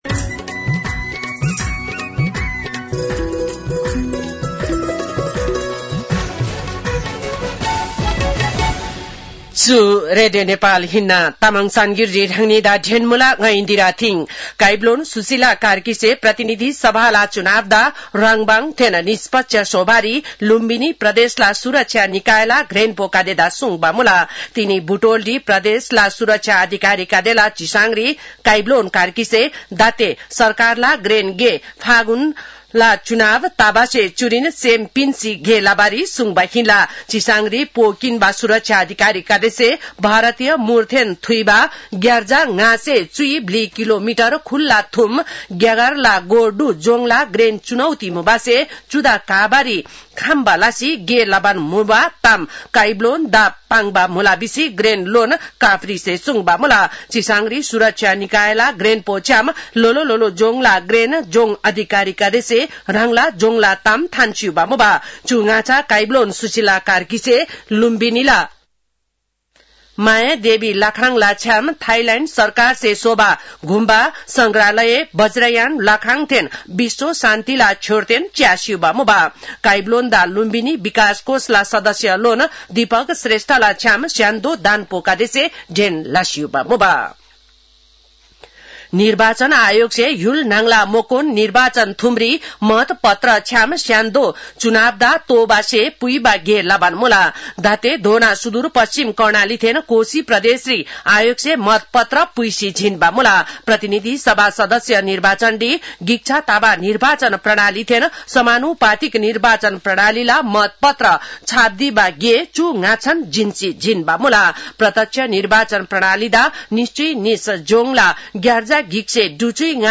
तामाङ भाषाको समाचार : ९ फागुन , २०८२